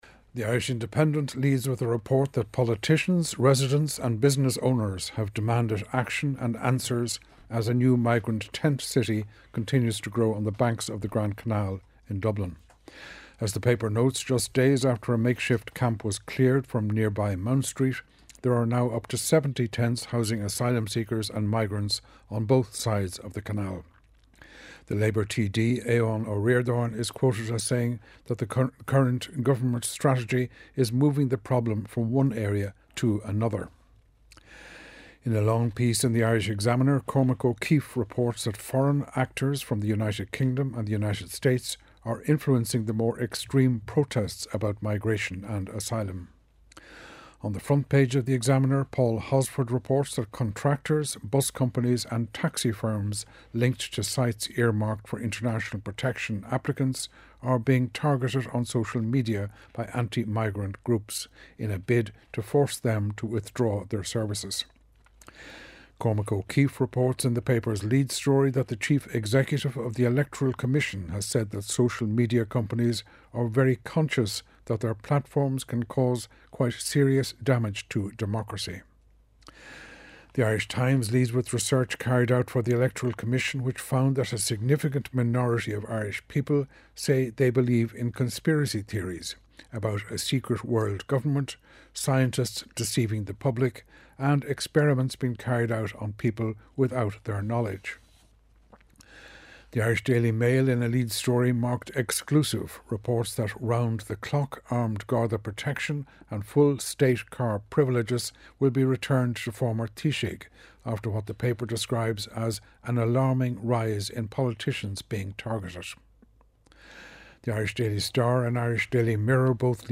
8.35am Sports News - 06.05.2024